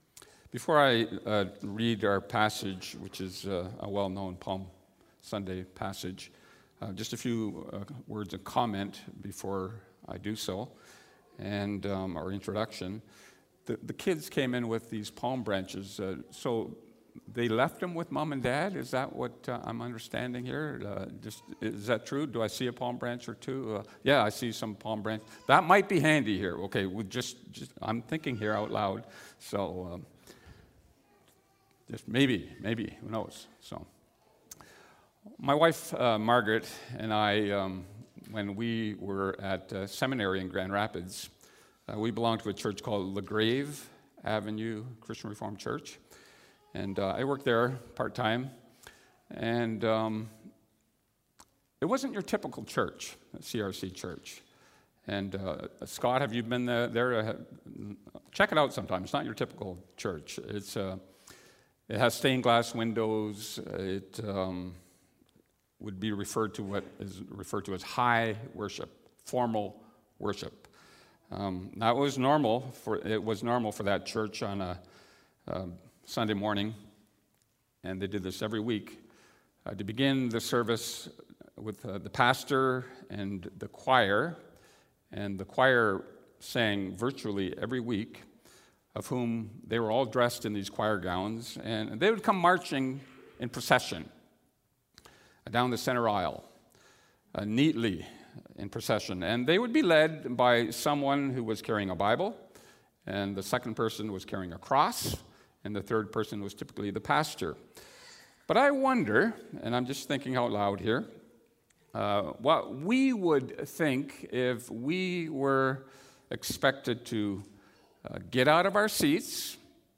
A Palm Sunday Sermon